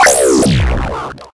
respawn.ogg